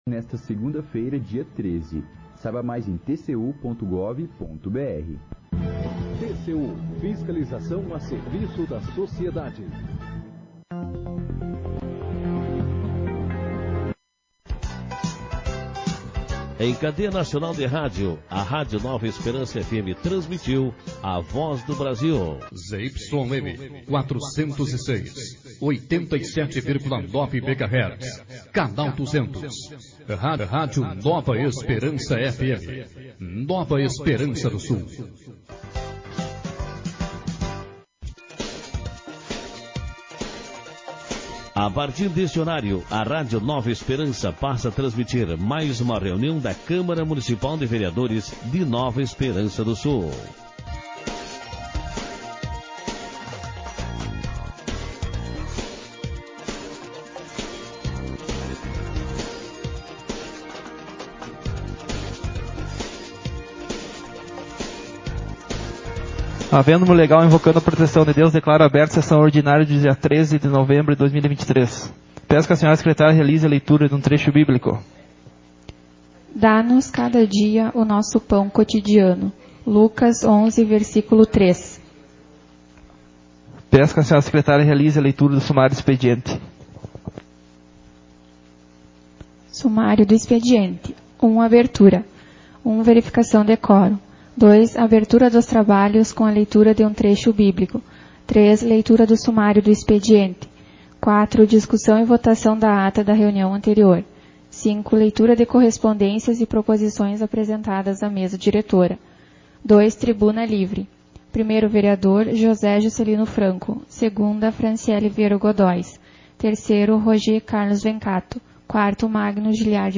Sessão Ordinária 37/2023